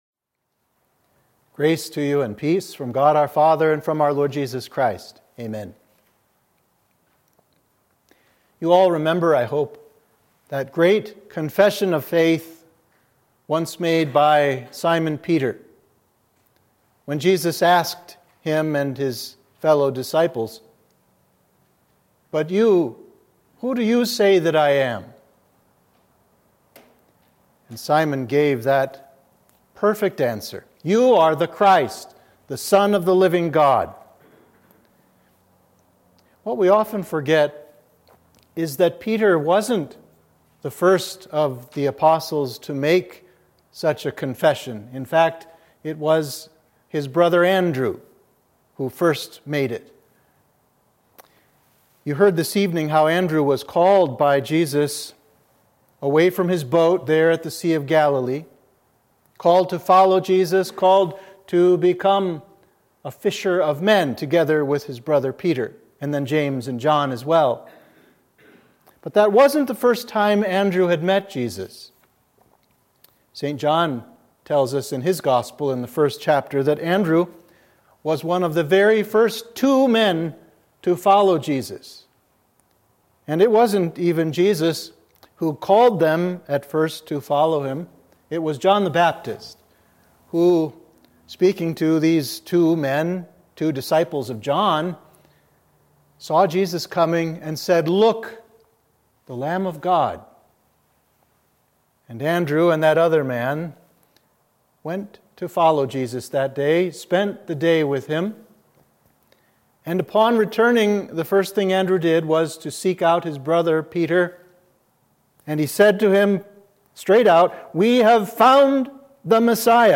Sermon (audio)
Sermon for St. Andrew’s Day – Week of Advent 1